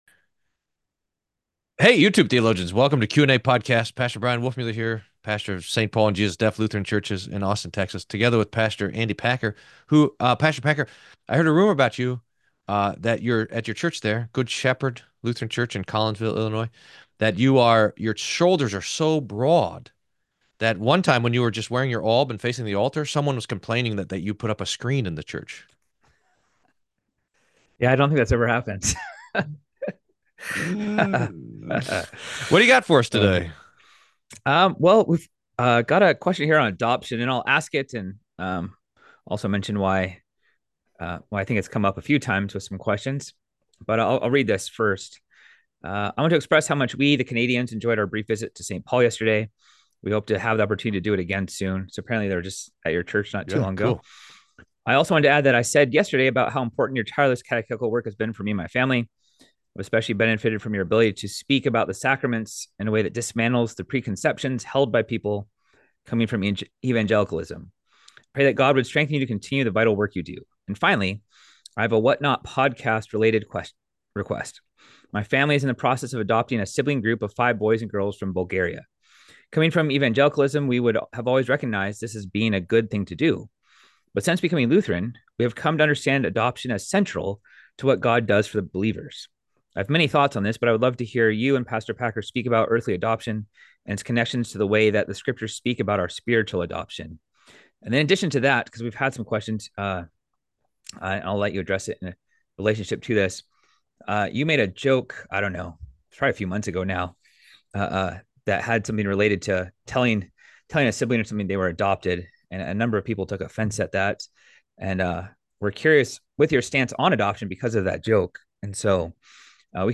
1 Signs of the Times: Be Alert! - A Sermon